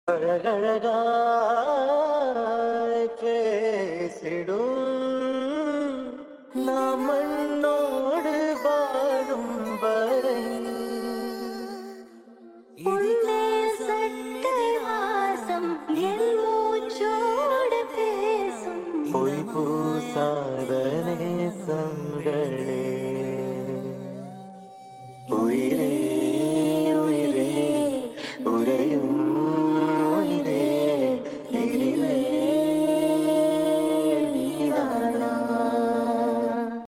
[audio edit + sped up edit]